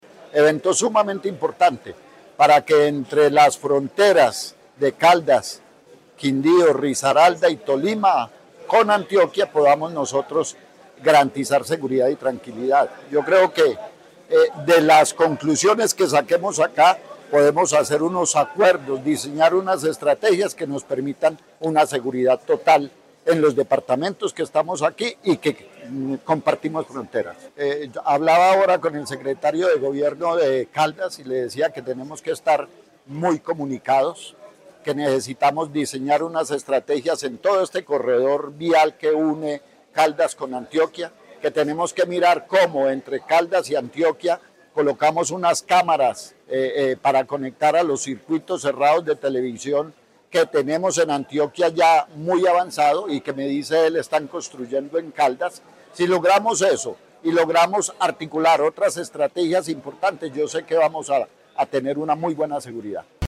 Luis Eduardo Martínez, Secretario de Seguridad y Justicia de Antioquia.